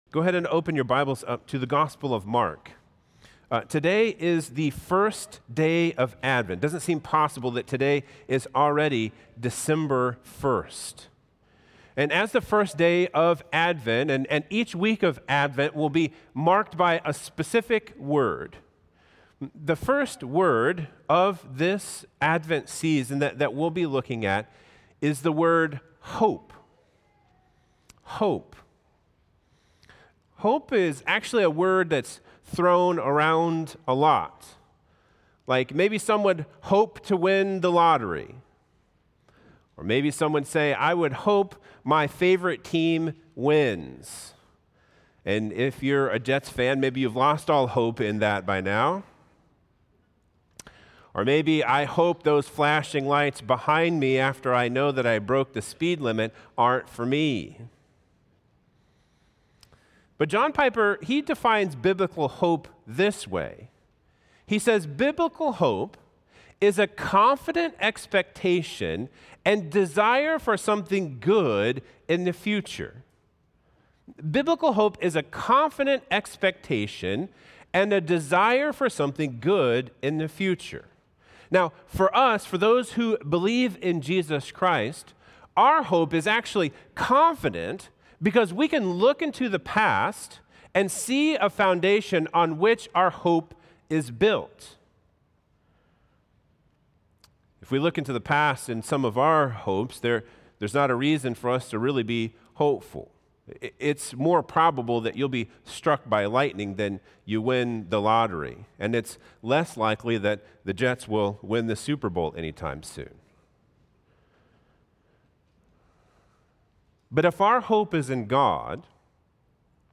Download Download Reference Mark 1:1-3; Isaiah 40:1-8 Sermon Notes 1- Hope!